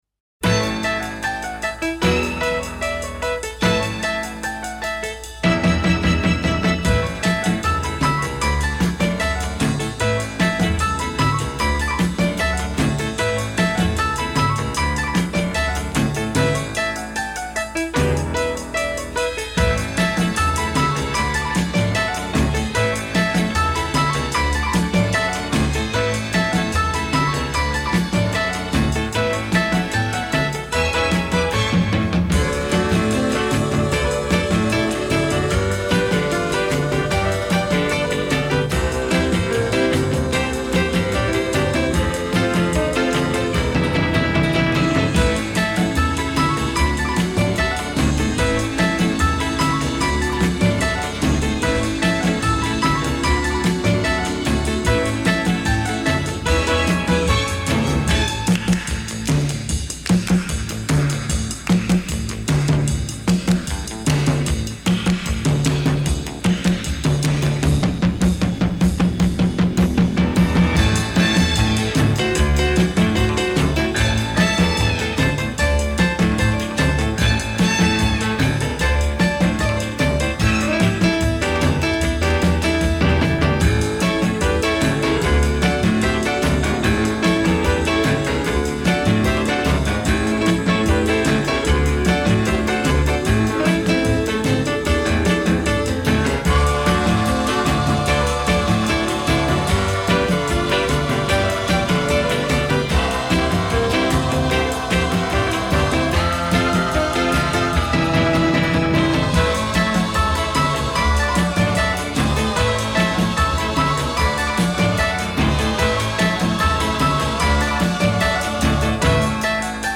#3 instrumental hit.